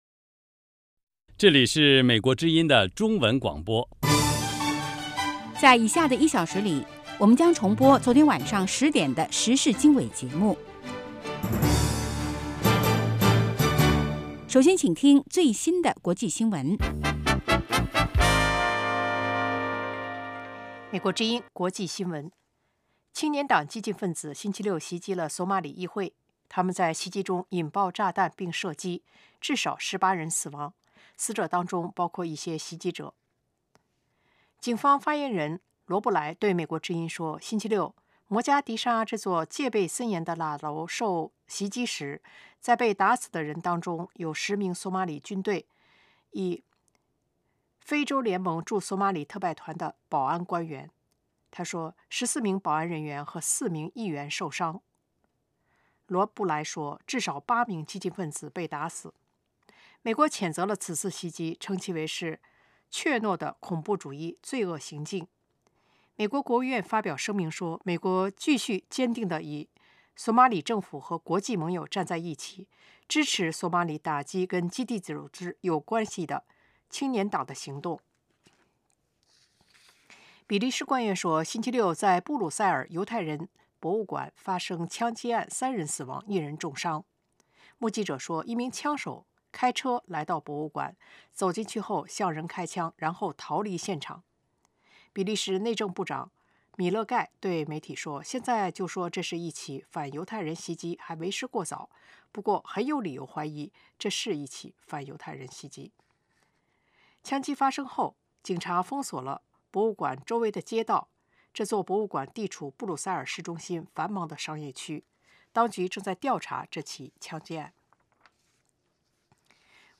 周一至周五：国际新闻 时事经纬(重播) 周六：时事经纬 听众热线 (重播) 北京时间: 上午8点 格林威治标准时间: 0000 节目长度 : 60 收听: mp3